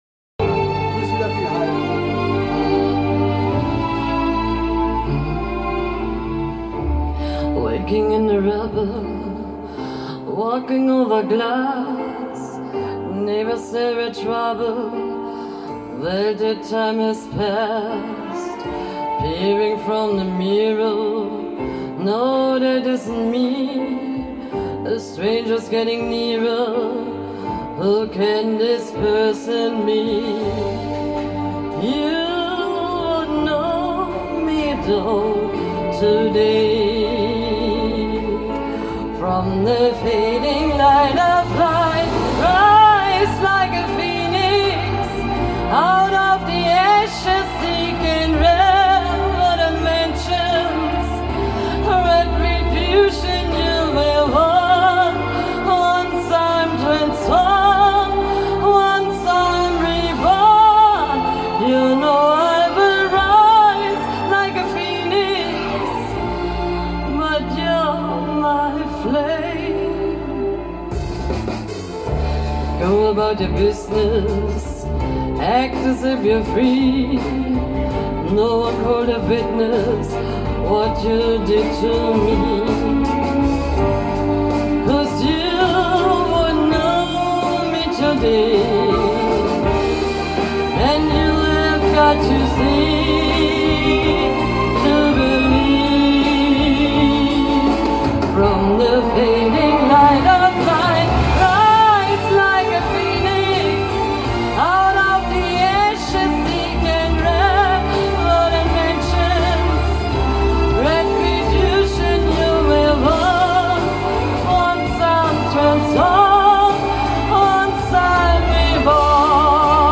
i-laute wie in "mean" finde ich zu spitz.